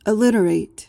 PRONUNCIATION:
(uh-LIT-uh-rayt)